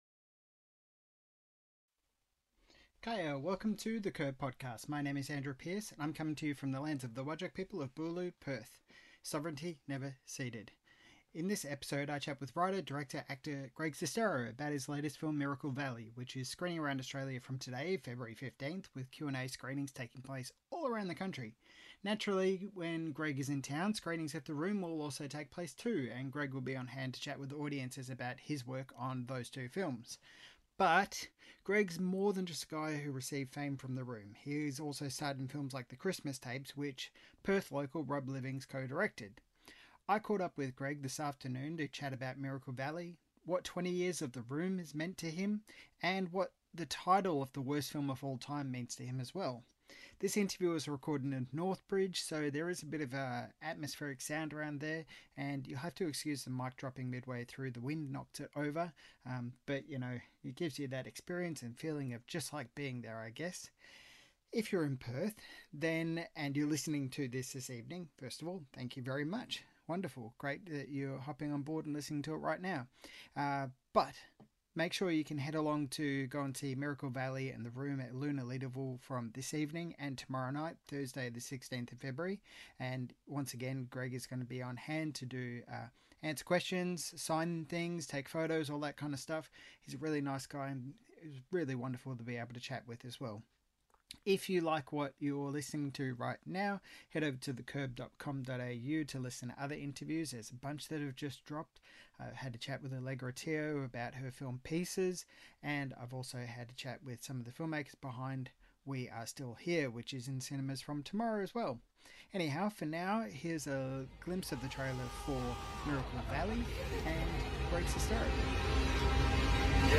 Greg talks about Miracle Valley, twenty years of The Room and what the title ‘the worst film of all time’ means to him. This interview was recorded in Northbridge so there is some atmospheric sound, and you’ll have to excuse the mic dropping midway through, the wind knocked it over.